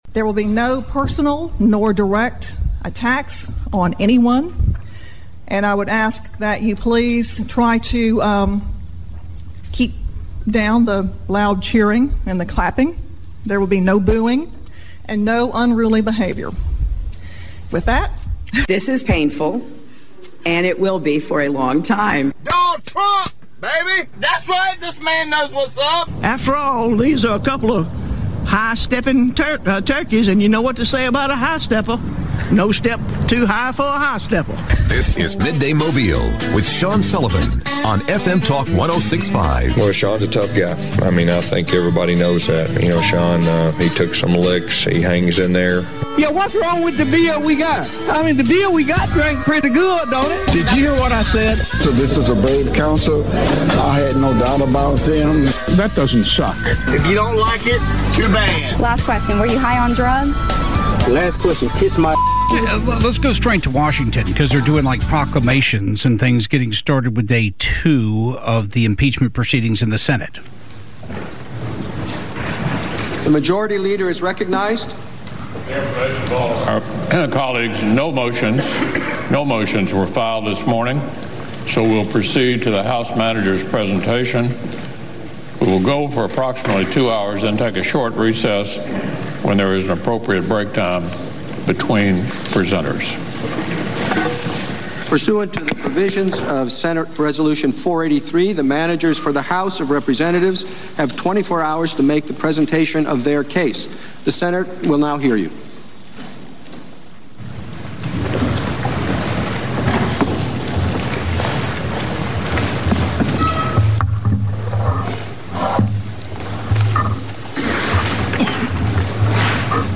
Mobile County Commissioner Jerry Carl joins the show to discuss the new bridge proposal. District 105 representative Chip Brown calls in from Montgomery to discuss state politics.